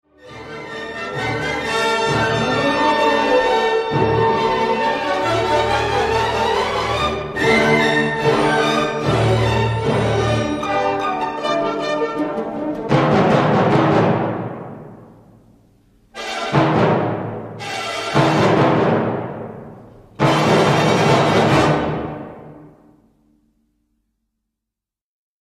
Zakończenie jest hałaśliwe i zuchwałe, ale dobrze oddaje parodystyczny charakter muzyki: